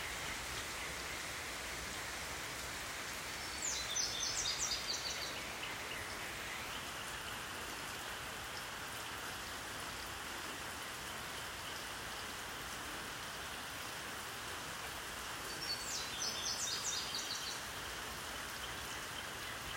I hear the birds singing outside my window and I join them in praise.
The sound of rain reminds me that He is generously providing life-giving water to His creation.
Beautiful photos, and I love your recording of the birds singing!
birds-in-the-rain.m4a